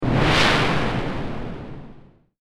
魔法 | 無料 BGM・効果音のフリー音源素材 | Springin’ Sound Stock
強風2.mp3